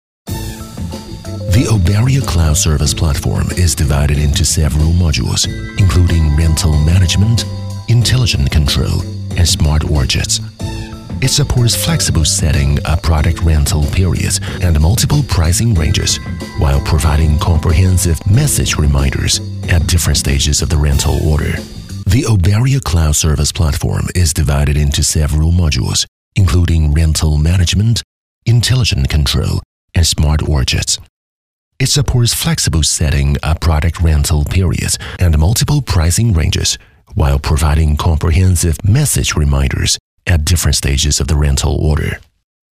【专题】美式 专题 中年音色 科技风格
【专题】美式 专题 中年音色 科技风格.mp3